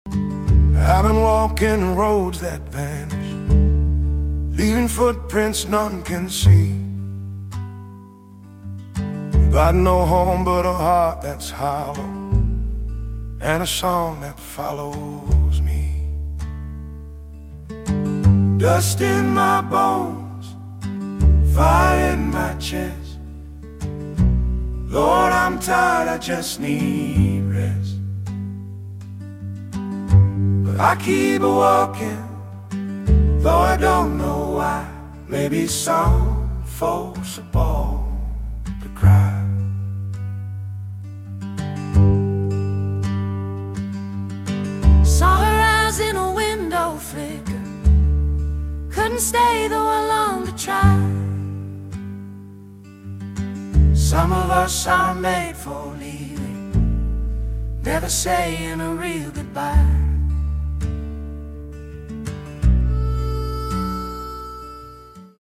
brings deep blues vibes. relaxing music